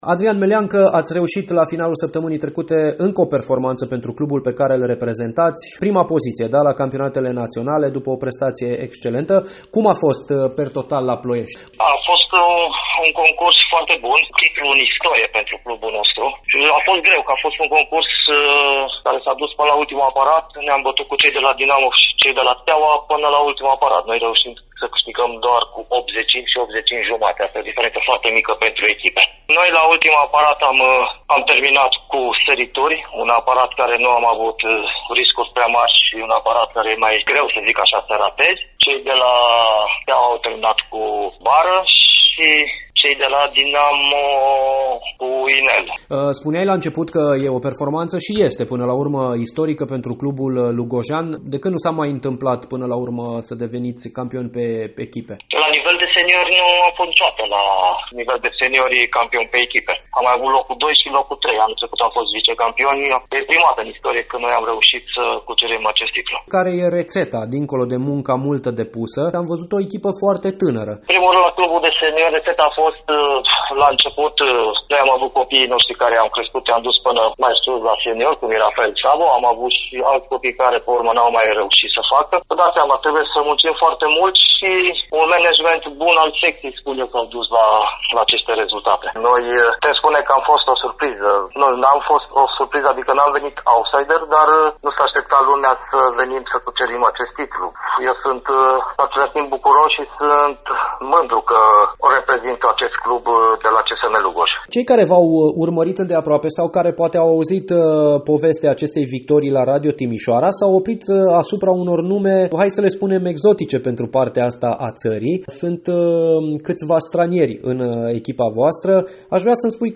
AR-15-sep-Reportaj-gimnasti-norvegieni-Lugoj.mp3